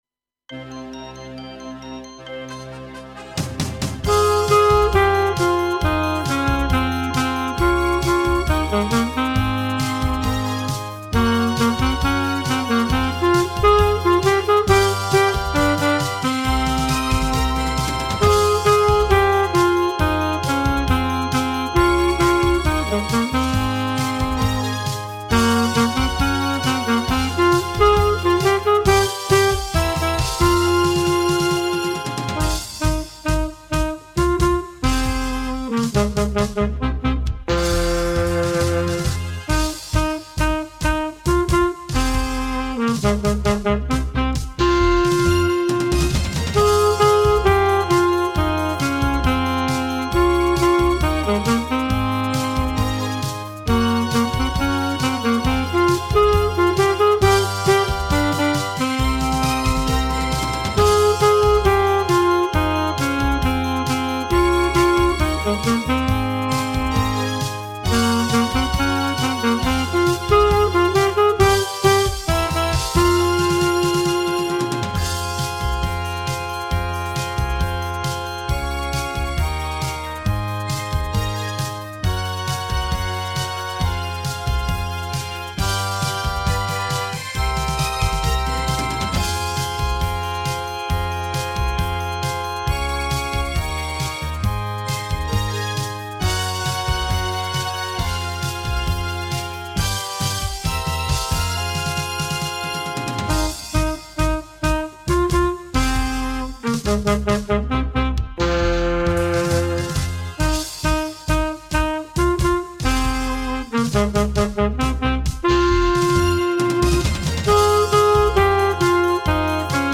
Avec saxophone alto lent 138